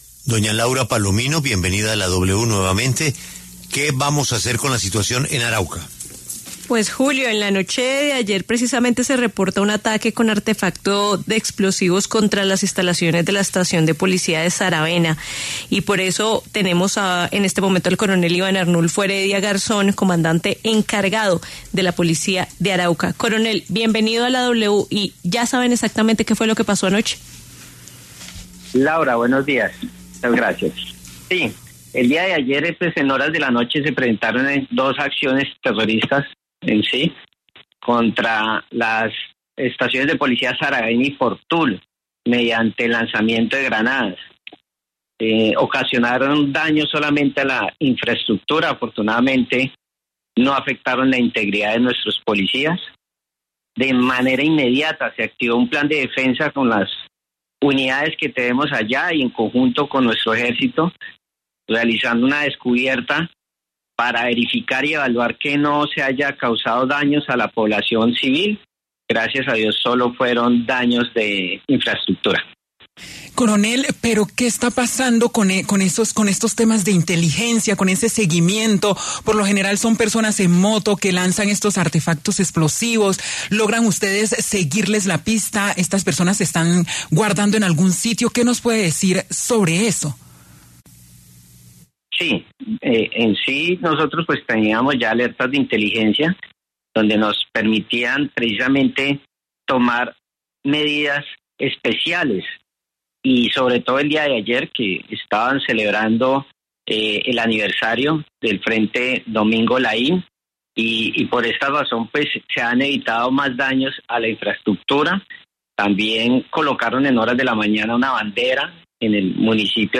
El coronel Iván Arnulfo Heredia Garzón, comandante encargado de la Policía de Arauca, informó que se están tomando medidas especiales para evitar este tipo de ataques.